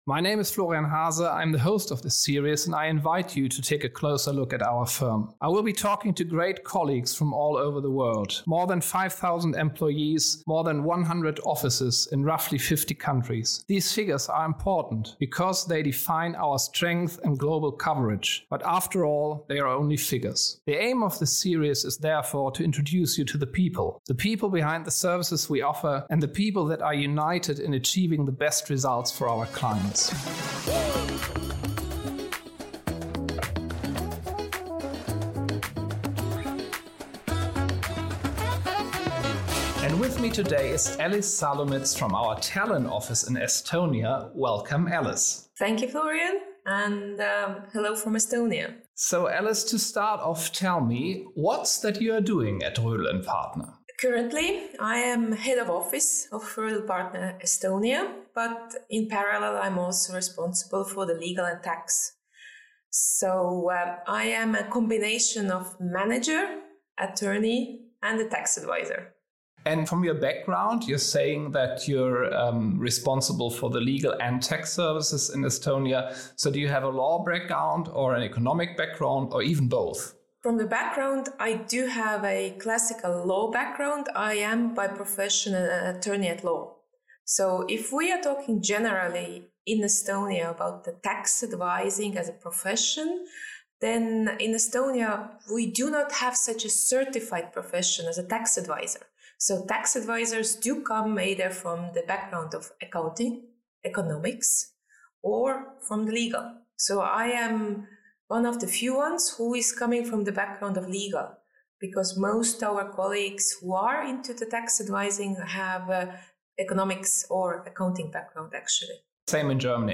Experience Tax Unplugged in a personal and humorous way.